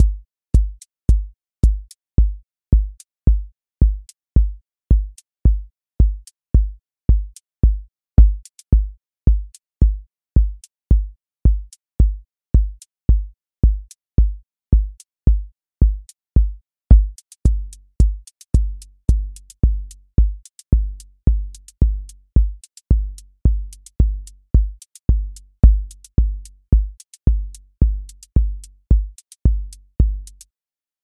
QA Listening Test house Template: four_on_floor
• macro_house_four_on_floor
• voice_kick_808
• voice_hat_rimshot
• voice_sub_pulse